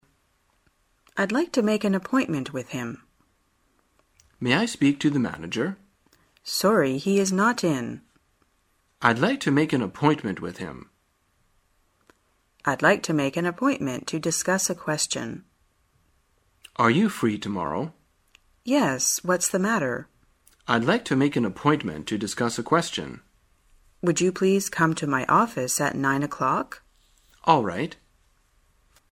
在线英语听力室生活口语天天说 第78期:怎样预约会面的听力文件下载,《生活口语天天说》栏目将日常生活中最常用到的口语句型进行收集和重点讲解。真人发音配字幕帮助英语爱好者们练习听力并进行口语跟读。